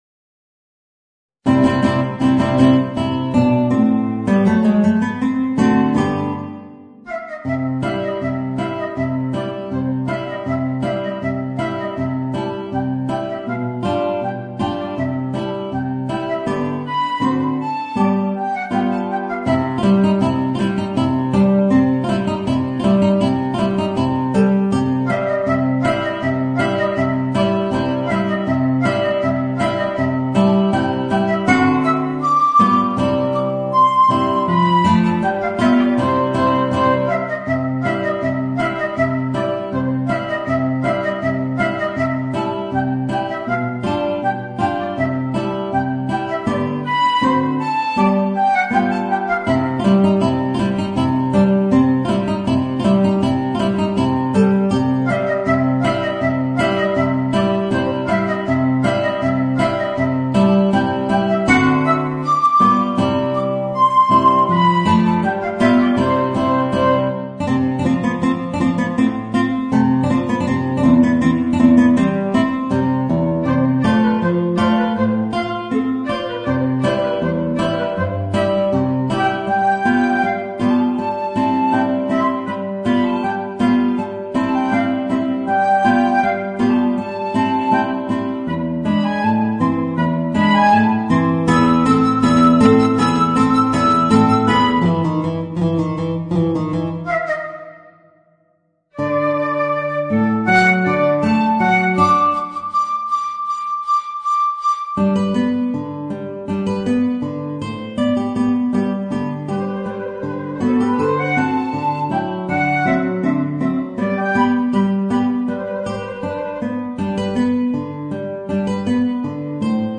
Voicing: Guitar and Flute